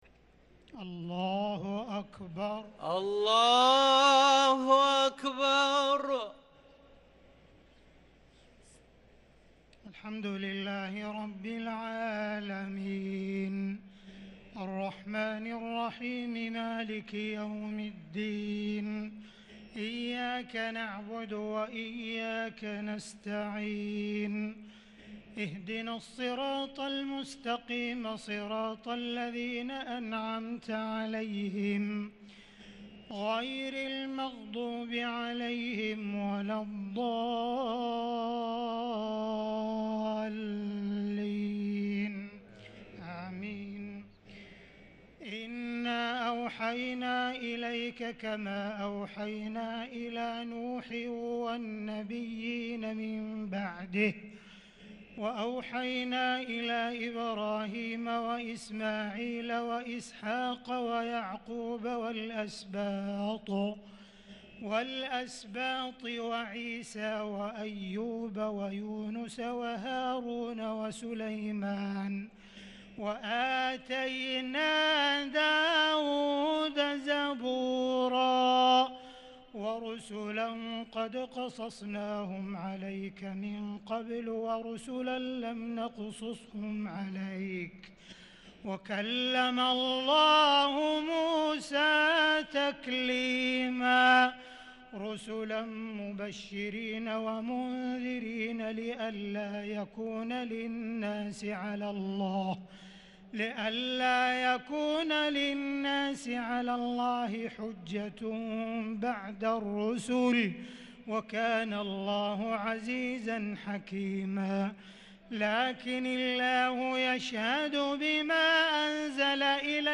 صلاة التراويح ليلة 7 رمضان 1443 للقارئ عبدالرحمن السديس - التسليمة الأخيرة صلاة التراويح